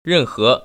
[rènhé] 런허